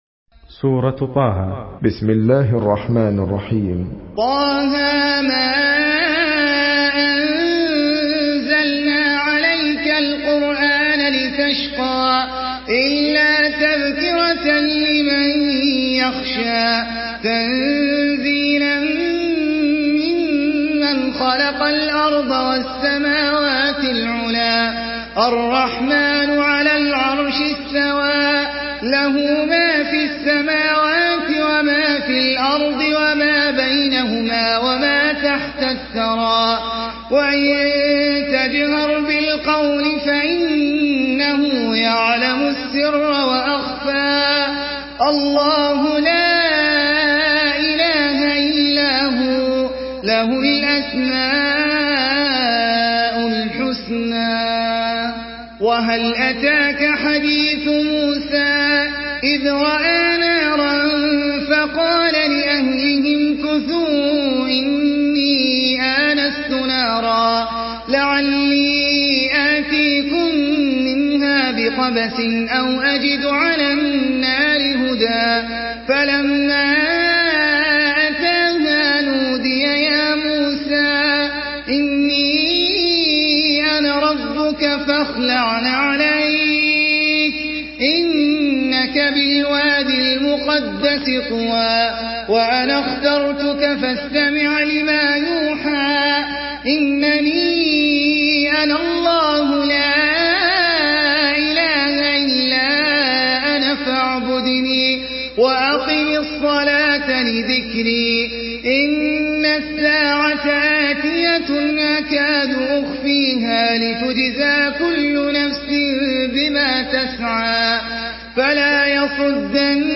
Surah طه MP3 by أحمد العجمي in حفص عن عاصم narration.
مرتل حفص عن عاصم